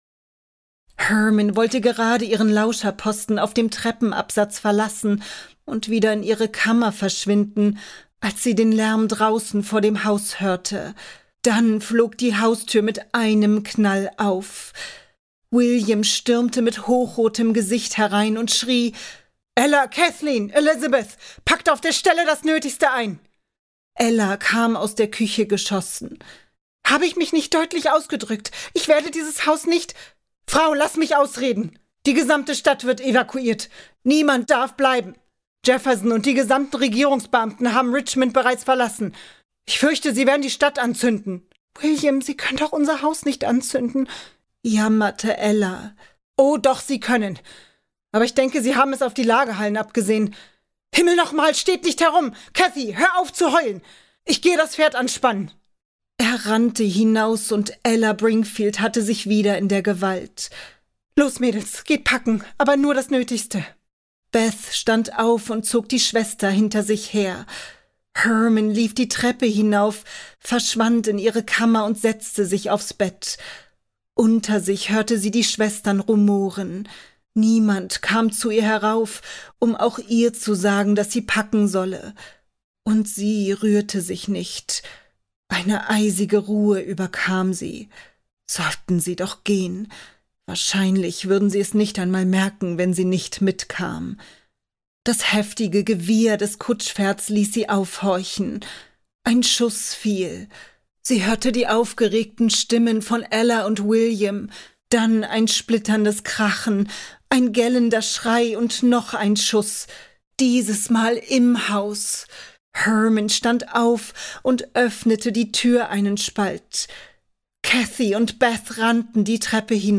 Dieser Band ist jetzt auch bei Piet Henry Records als Hörbuch  erschienen!